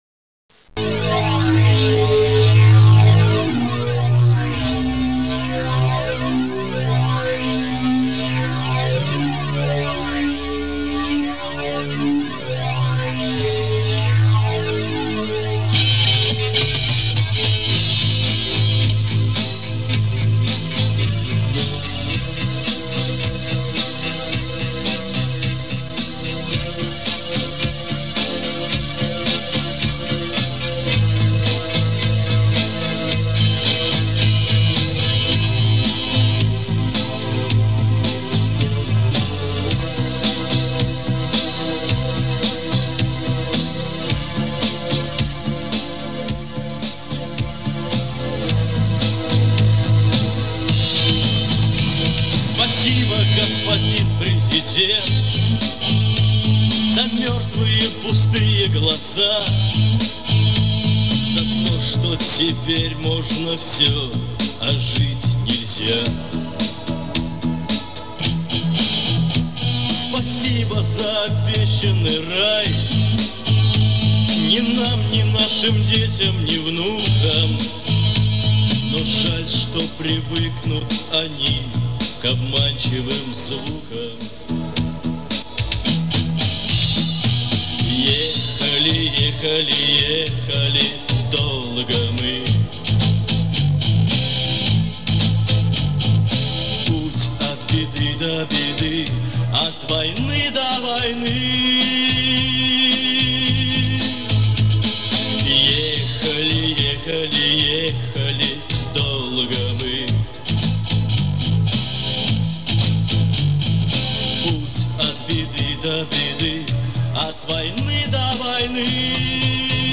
Похоже, что у вас редкая концертная запись.